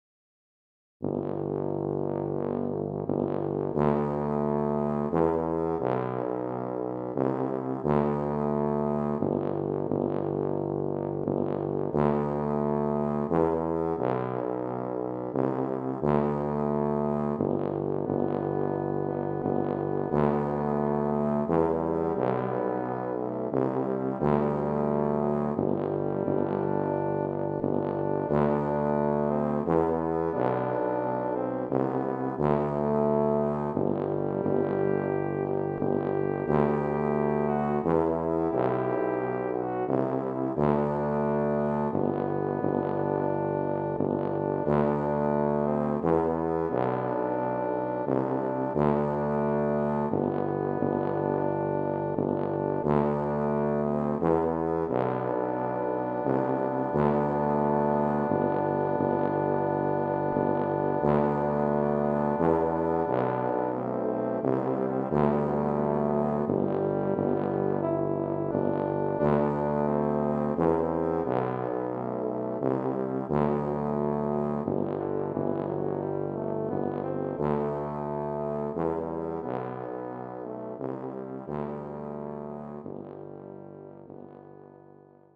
Voicing: Low Brass Trio